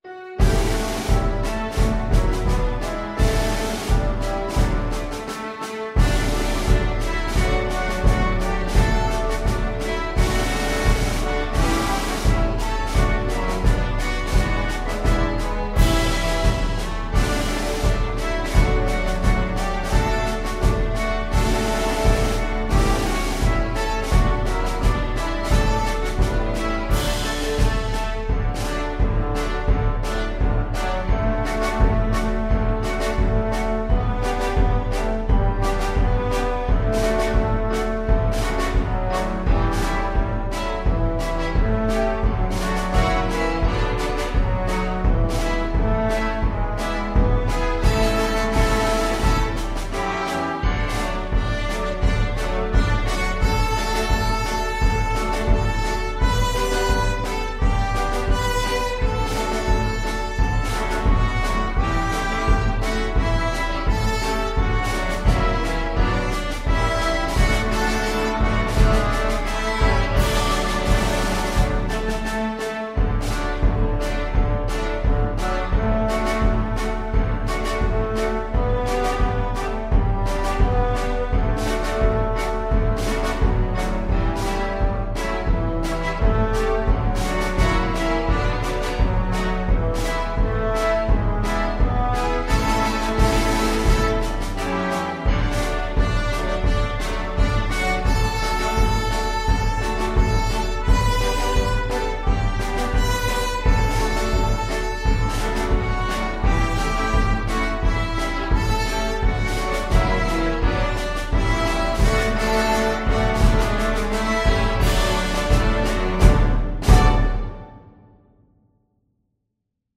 FluteTrumpetViolin
2/2 (View more 2/2 Music)
Tempo di Marcia = c.86